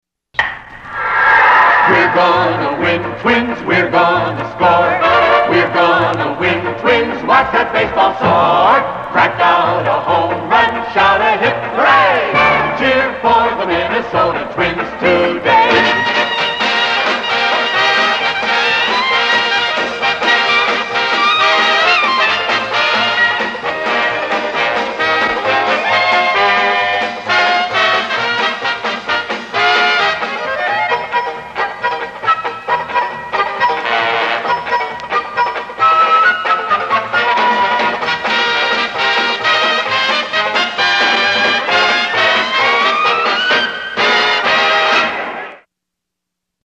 it really swings!